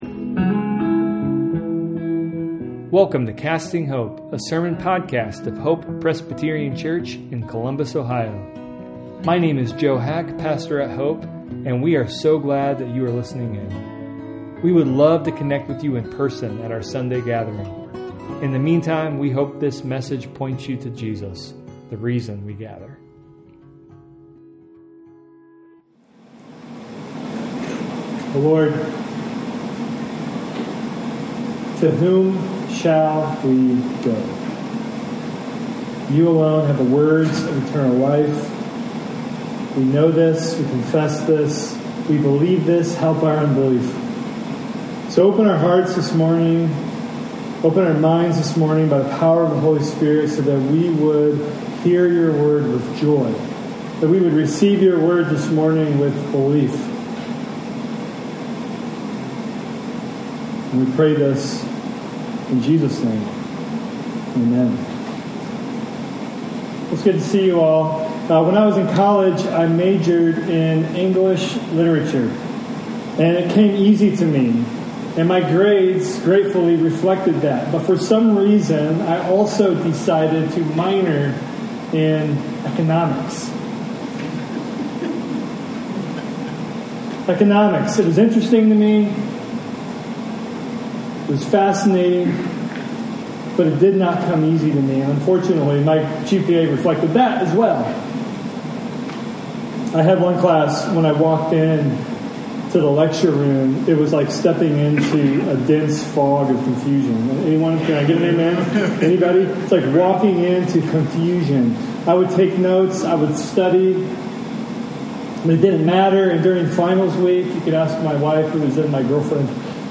A sermon podcast of Hope Presbyterian Church in Columbus, Ohio.